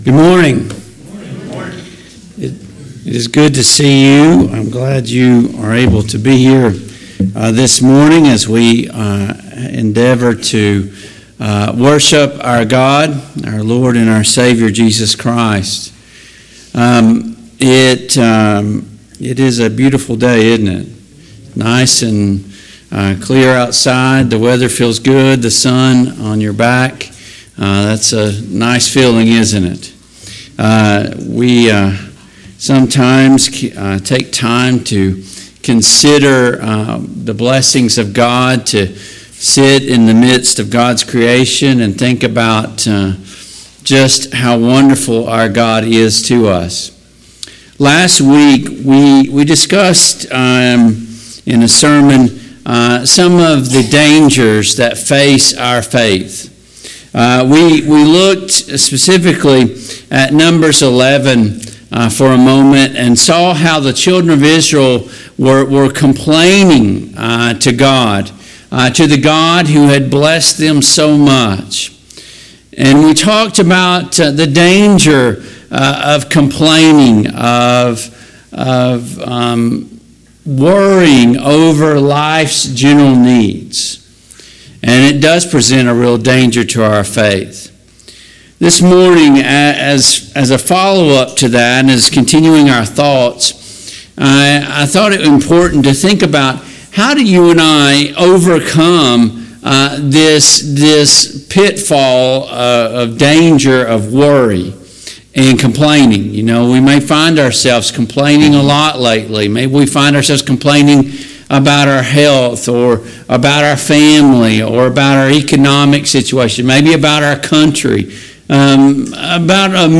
Service Type: AM Worship Topics: Gratitude , Thanksgiving